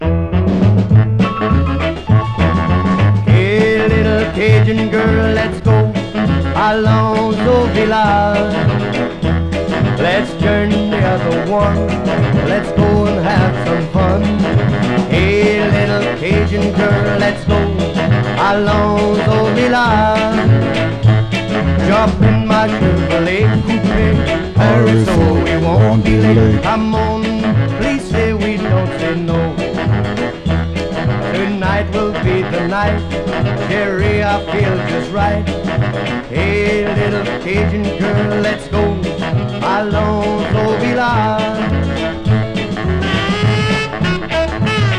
Rock, Pop, Swamp　USA　12inchレコード　33rpm　Stereo